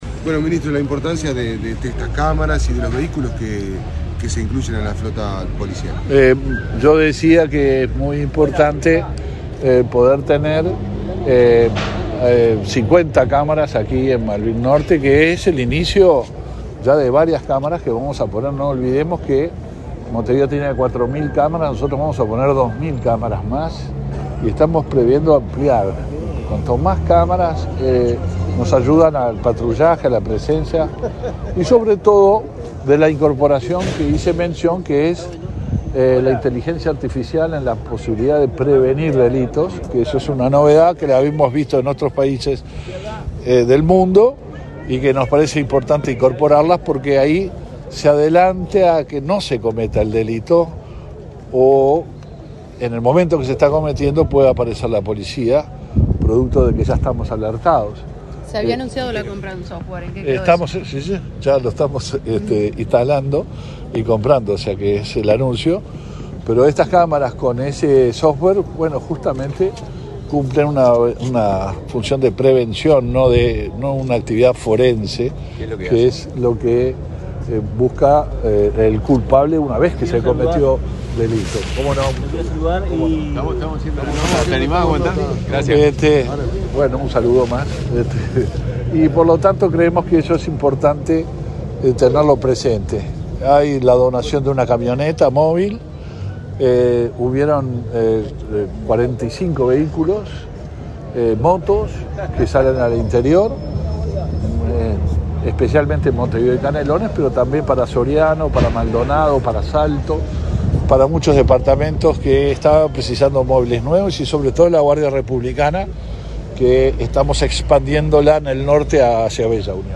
Declaraciones a la prensa del ministro del Interior, Luis Alberto Heber
Declaraciones a la prensa del ministro del Interior, Luis Alberto Heber 18/08/2023 Compartir Facebook X Copiar enlace WhatsApp LinkedIn Este 18 de agosto comenzó a funcionar el comando móvil en el barrio Malvín, de Montevideo, con 50 cámaras de videovigilancia, y se entregaron 45 vehículos. Tras el evento, el ministro Luis Alberto Heber, realizó declaraciones a la prensa.